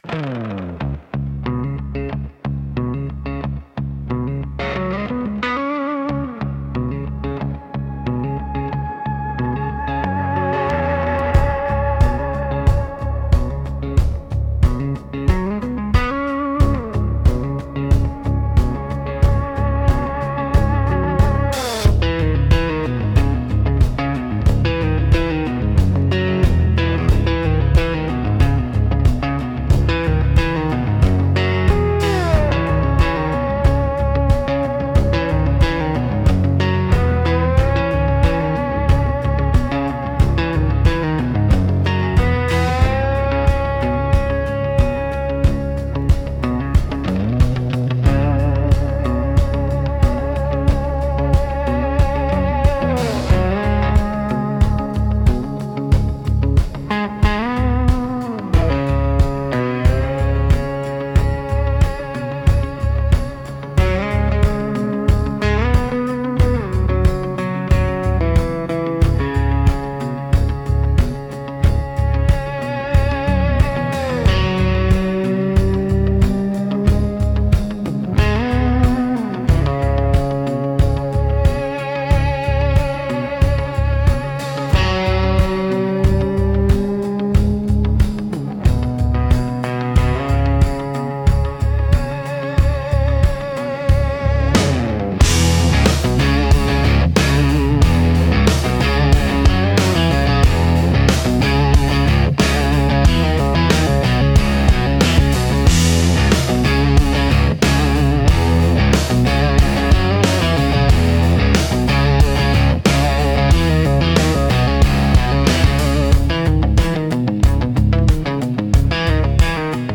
Instrumental - Wire and Bone Serenade 4.34